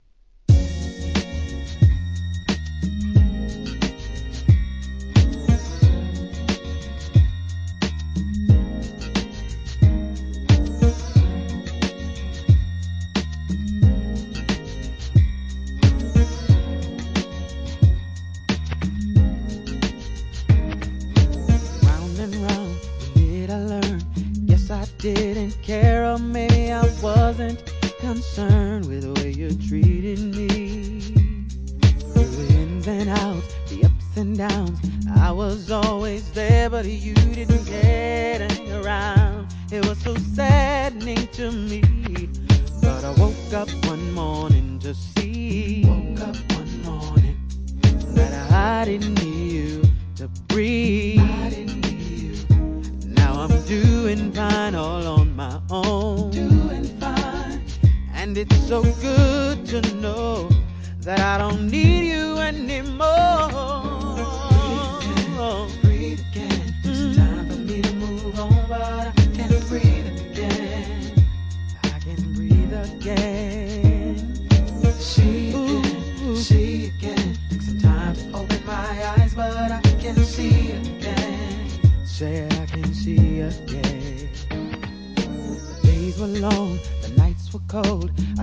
HIP HOP/R&B
伸びのある柔軟なヴォーカルで聴かせる2001年のインディーR&B!!